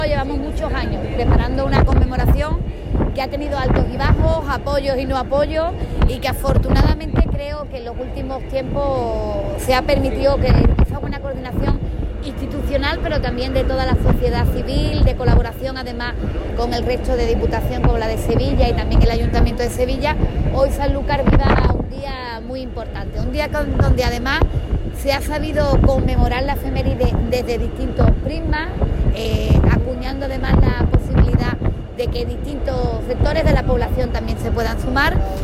La presidenta participa en el homenaje de la Armada a los navegantes que completaron la gesta